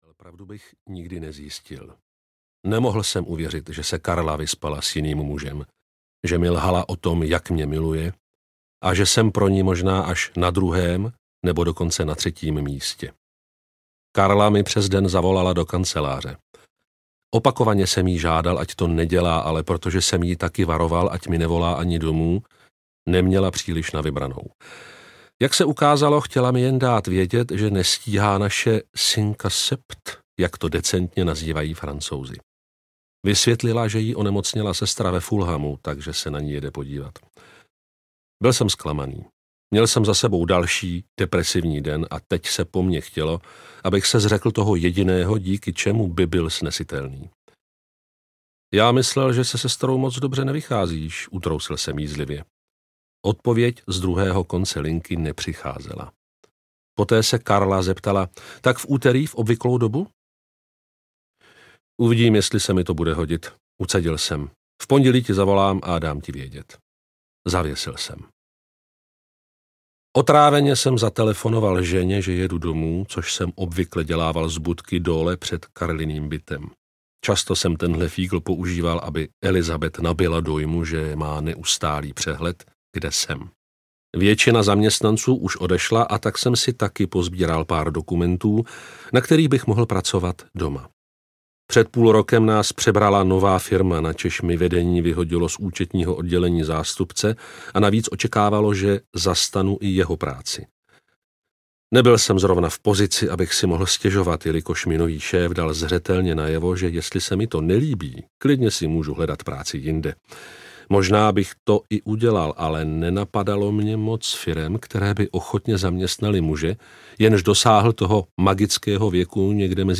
Všechno je jinak audiokniha
Ukázka z knihy